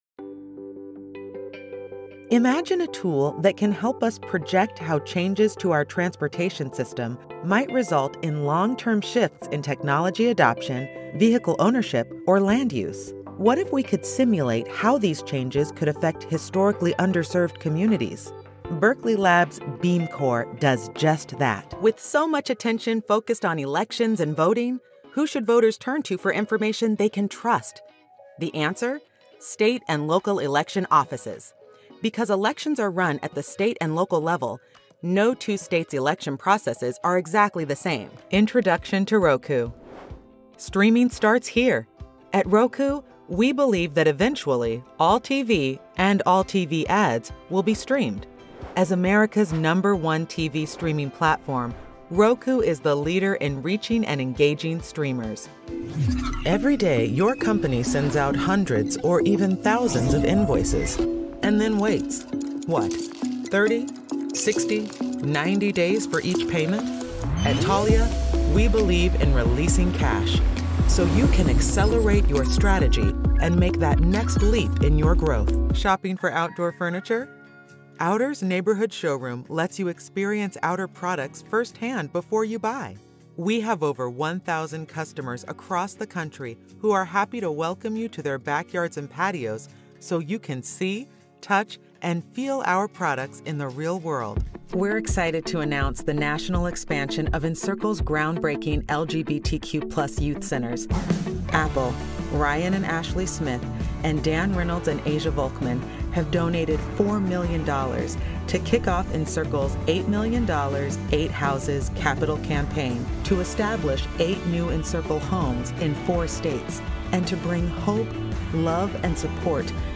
Professional, Talented, Female Voice Over Actor
She is equipped with a sound isolated Studiobricks home studio and a Neumann TLM 103 microphone. Her voice is conversational, warm, friendly, upbeat, relatable, millennial and credible.